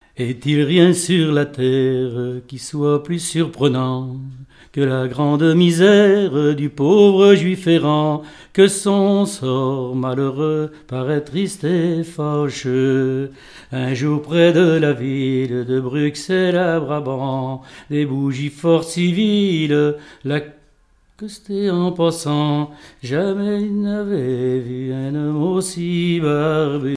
Genre strophique
Enquête Compagnons d'EthnoDoc - Arexcpo en Vendée
répertoire de chansons, et d'airs à danser
Pièce musicale inédite